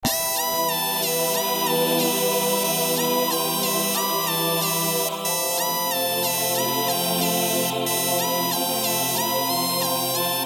唱诗班领唱
描述：唱诗班垫子 + 嘻哈线索。这类循环没有一个真正的类别，所以我称之为组合循环。
Tag: 92 bpm Hip Hop Loops Pad Loops 1.76 MB wav Key : Unknown